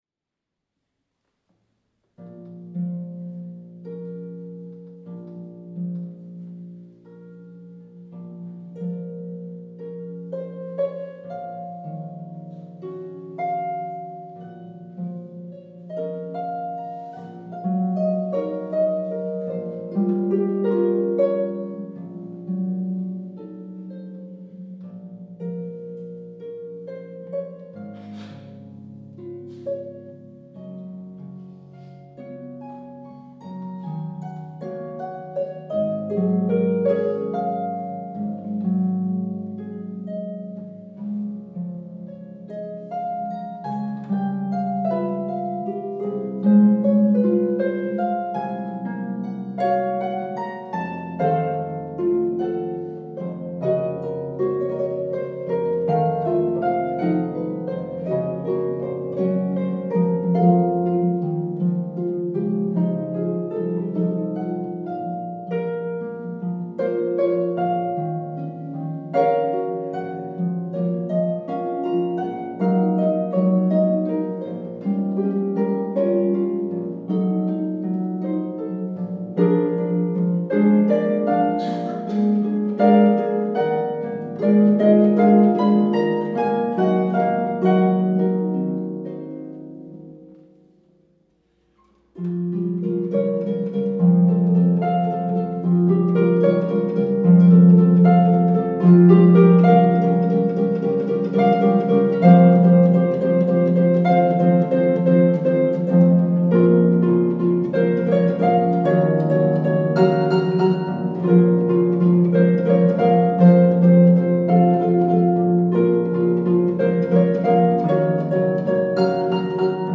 two pedal harps